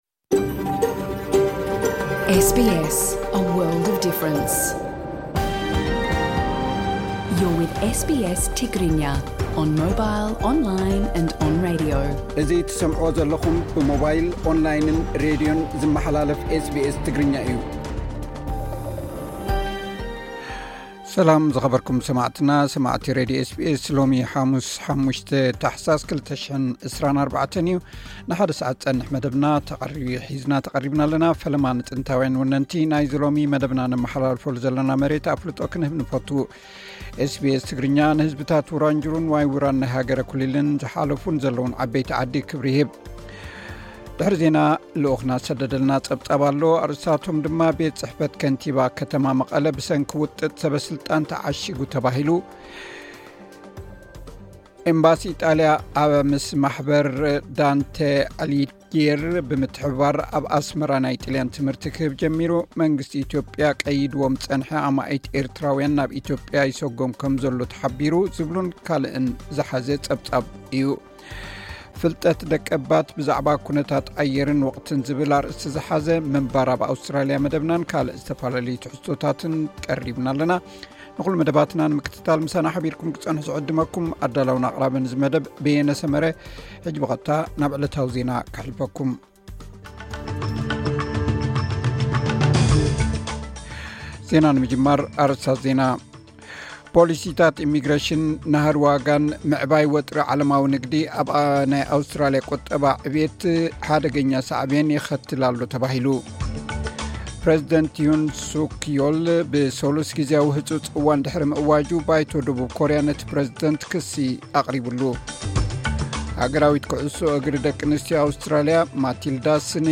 ዝብሉ ኣርእስታት ዝሓዘ ሉኡኽና ዝሰደደልና ጸብጻብ ኣለና። ፍልጠት ደቀባት ብዛዕባ ኩነታት ኣየርን ወቕትን ዝብል ኣርእስቲ ዝሓዘ ምንባር ኣብ ኣውስትራሊያን ካልእ ዝተፈላለዩ ትሕዝቶታትውን ኣዳሊና ኣለና።